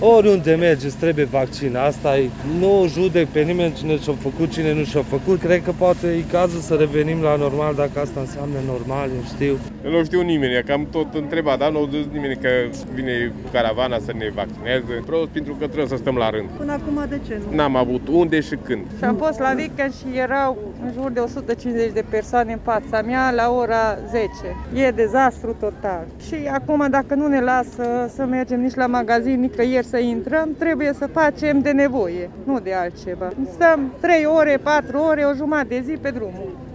Târgumureșenii prezenți astăzi la vaccinare au recunoscut că introducerea obligativității certificatului verde în multe unități i-a determinat să se vaccineze, și au fost nemulțumiți că trebuie să aștepte la coadă.